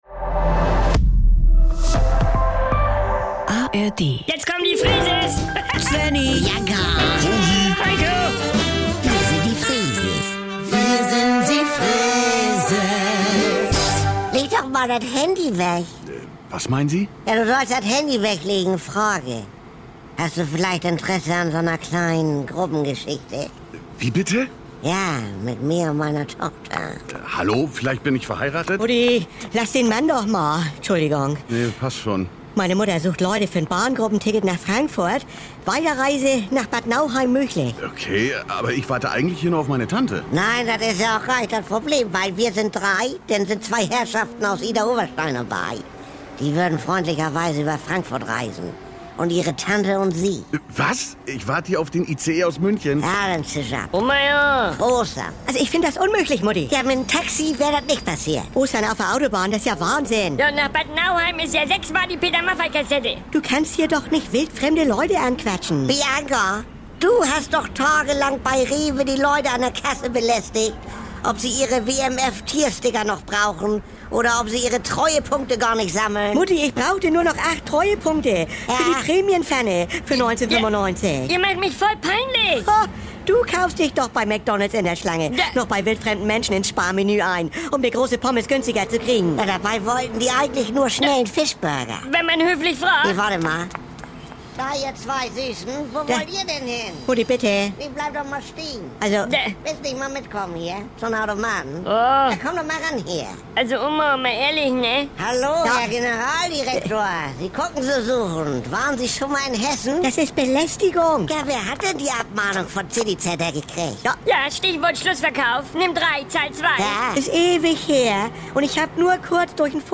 … continue reading 1890 episoder # Saubere Komödien # NDR 2 # Komödie # Unterhaltung